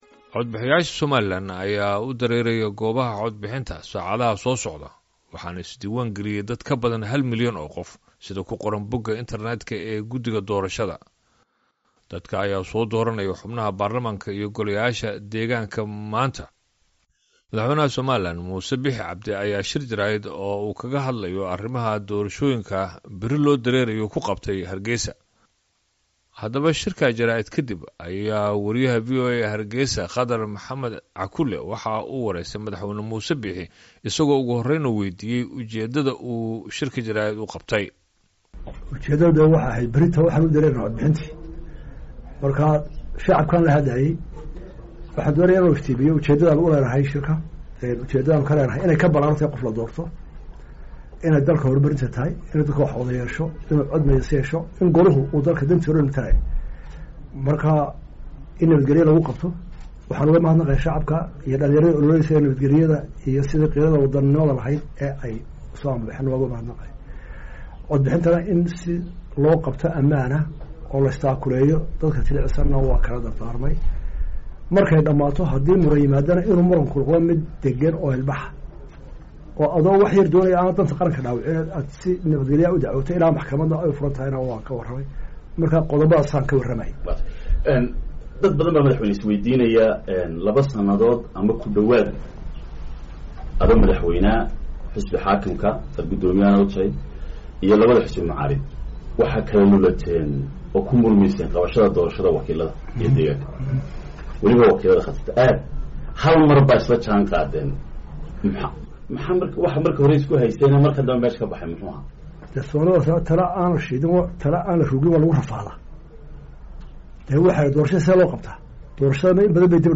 Wareysi: Madaxweyne Muuse Biixi oo ka hadlay doorashooyinka